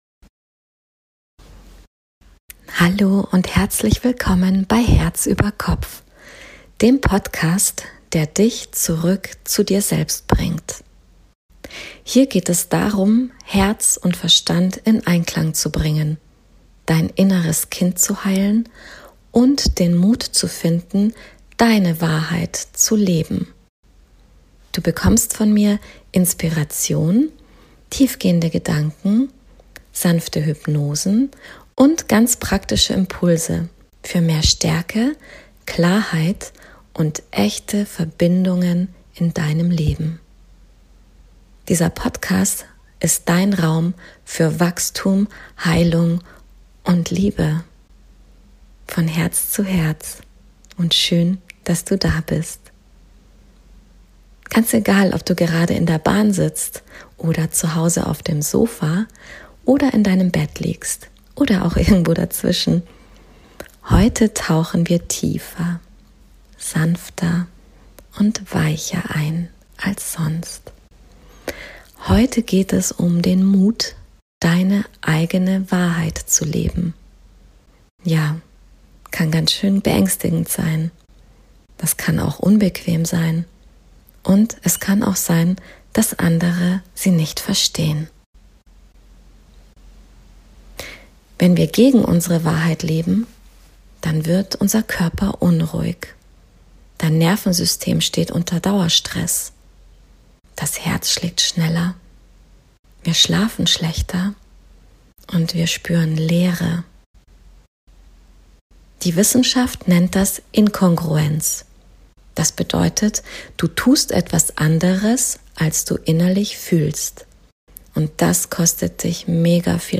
Mut zur eigenen Wahrheit – eine hypnotische Meditation für Dein inneres Kind ~ Herz über Kopf Podcast
Und wie Du dabei sicher, geliebt und gehalten bleibst, auch wenn Deine Wahrheit Angst macht oder unbequem für andere ist. Mit einer tiefen Hypnose für Dein inneres Kind schenke ich Dir Geborgenheit, Liebe und den Mut, Dich nie wieder zu verlassen. Diese Episode ist wie eine Gute-Nacht-Geschichte für Dein Herz – voller Heilung, Klarheit und Wärme.